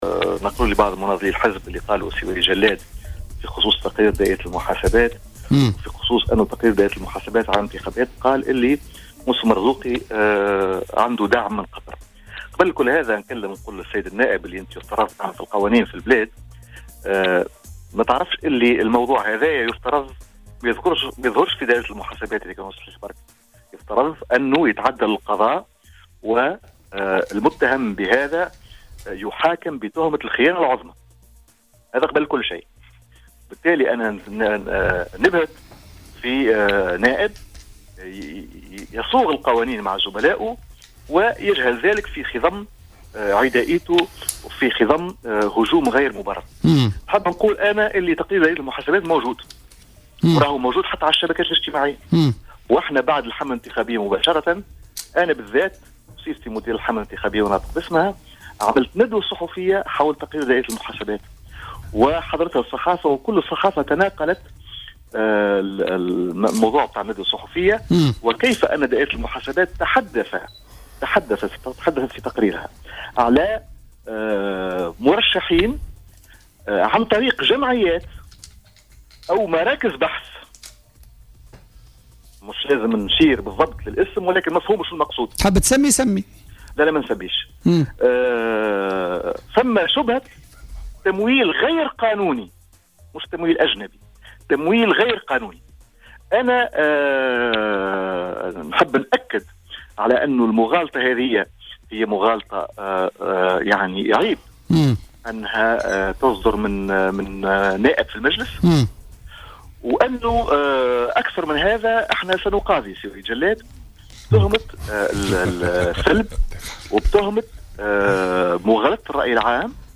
وقال منصر بصفته رئيس الحملة الانتخابية الرئاسية للمرزوقي، خلال مداخلة له في برنامج 'بوليتيكا،' اثر طلبه حق الرد، إنه سيتم رفع دعوى قضائية ضد النائب بتهمة الثلب ومغالطة الرأي العام، مشيرا إلى أن تقرير دائرة المحاسبات تضمّن جمع تفاصيل تمويل الحملة الانتخابية، للمرزوقي.